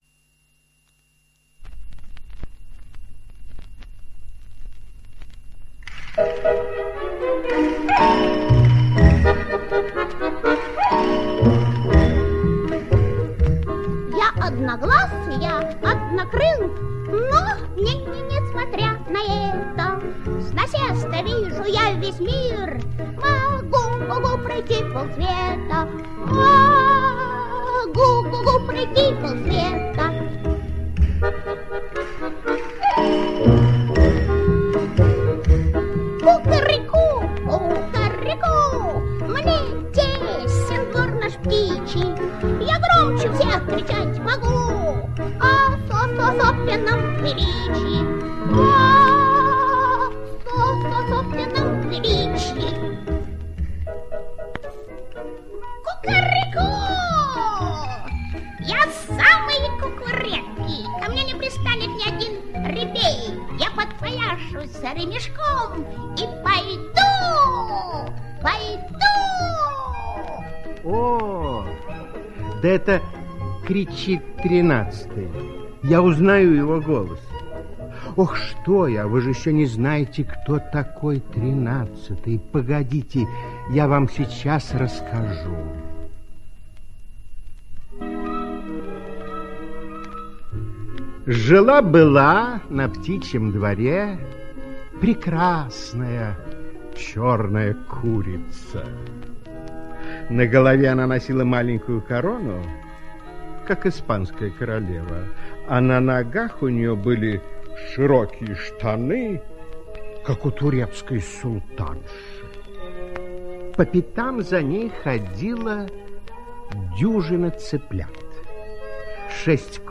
Как петушок попал на крышу - аудиосказка Лабулэ - слушать